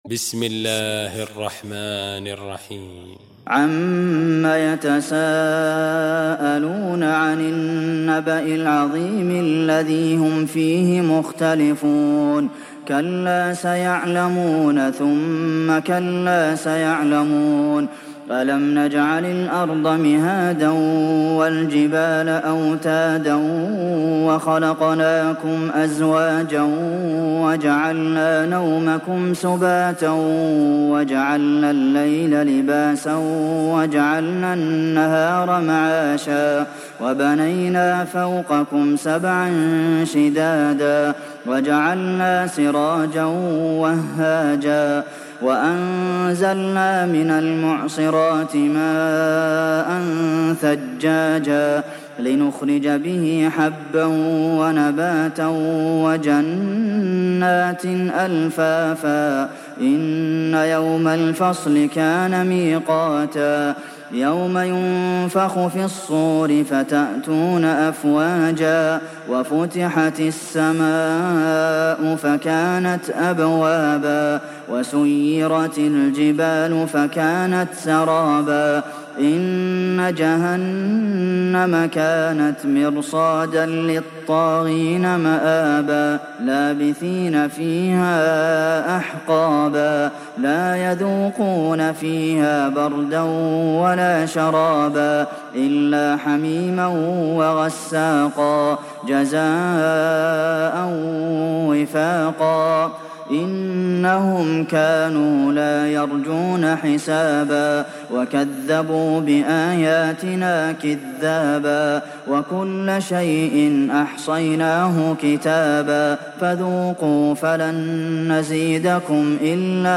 دانلود سوره النبأ mp3 عبد المحسن القاسم روایت حفص از عاصم, قرآن را دانلود کنید و گوش کن mp3 ، لینک مستقیم کامل